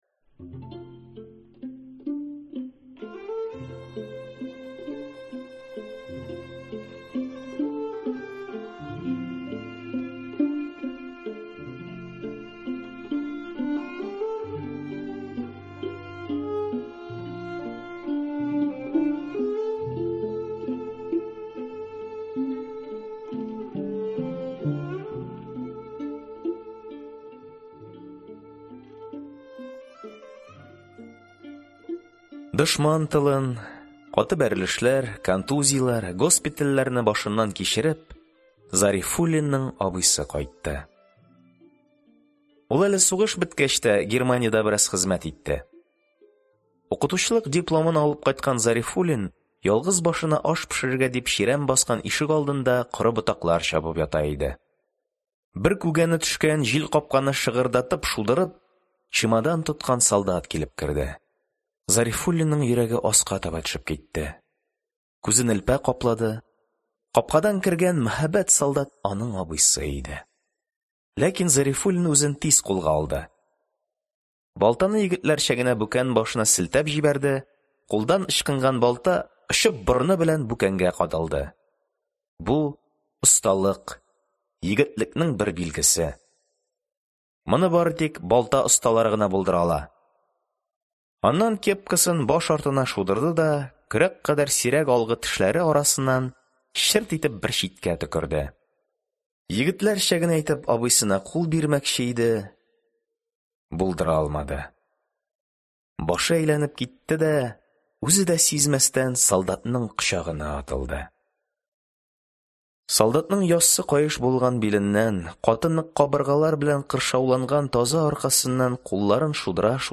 Аудиокнига Без - кырык беренче ел балалары | Библиотека аудиокниг